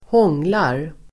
Uttal: [²h'ång:lar]